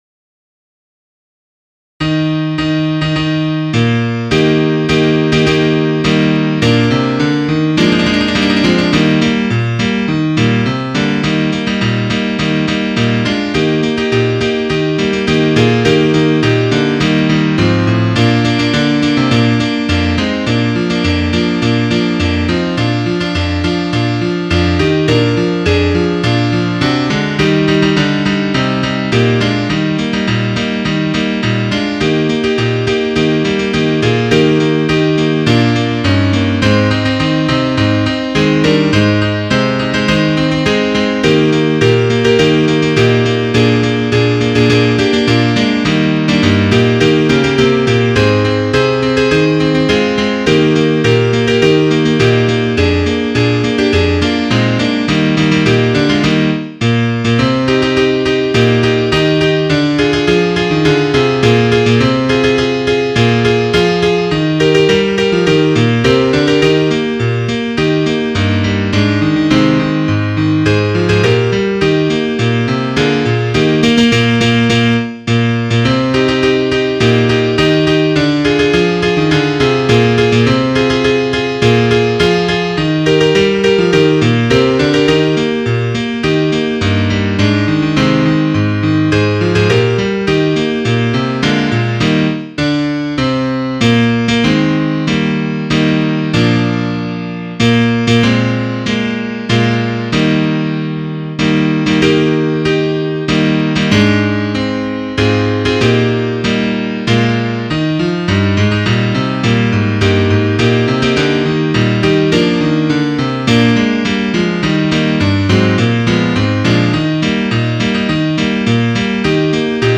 Musik für Ständchen
für Bläserquartett mit variabler Besetzung,